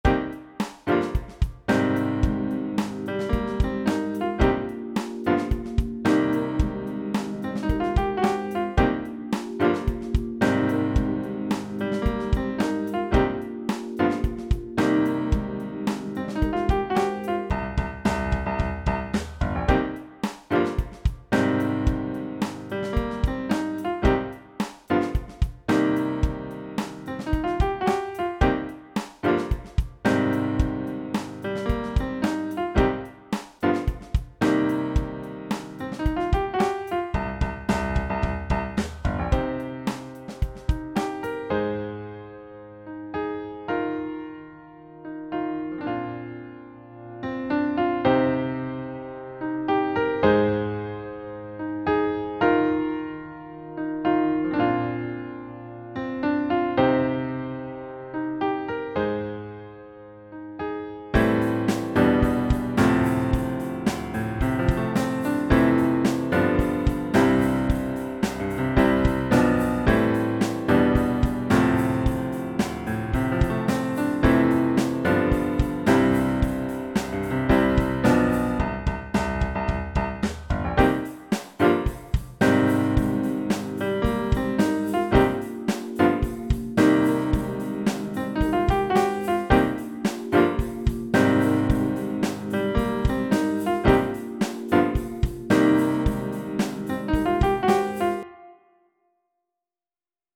Home > Music > Jazz > Bright > Smooth > Medium